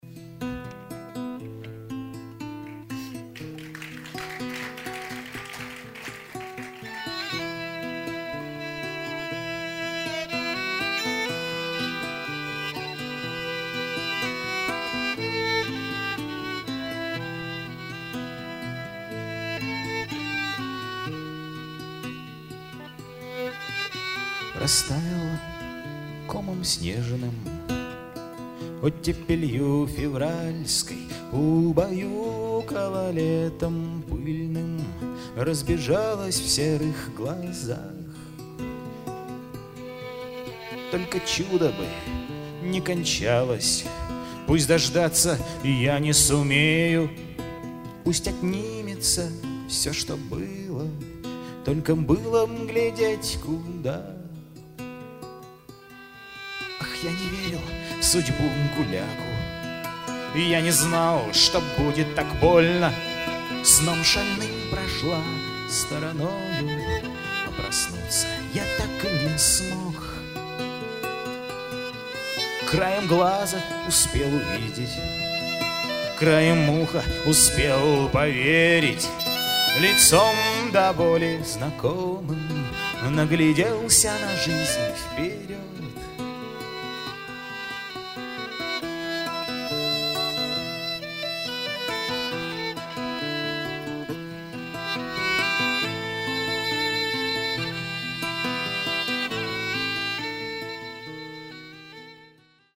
контрабас, бас-гитара
перкуссия